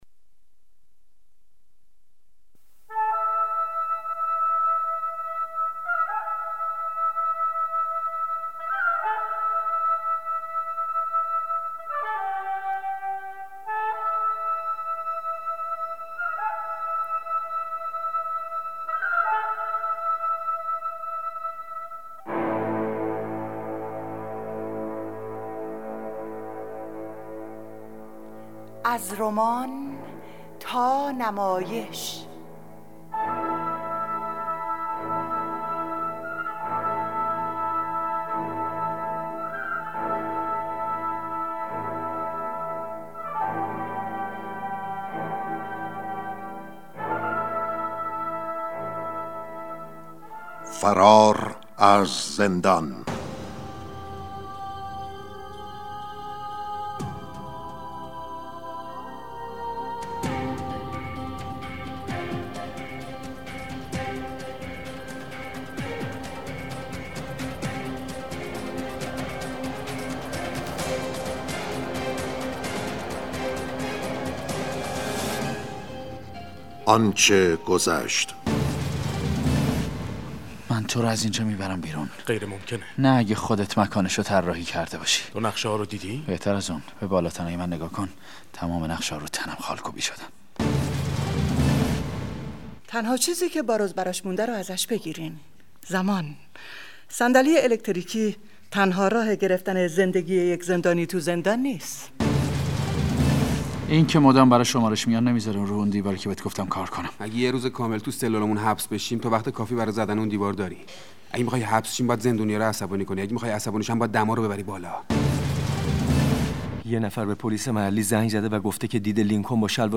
نمایش رادیویی «فرار از زندان» به کارگردانی ایوب آقاخانی در ۲۱ قسمت تولید و پخش شد.